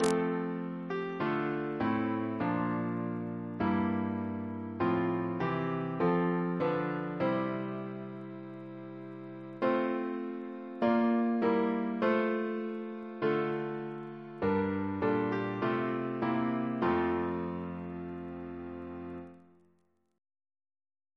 Double chant in F Composer: Robert Cooke (1768-1814), Organist of Westminster Abbey Reference psalters: ACB: 11; CWP: 162; OCB: 82; PP/SNCB: 17; RSCM: 34